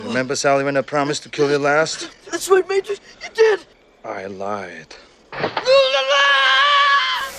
One of the most iconic Arnold Schwarzenegger quotes.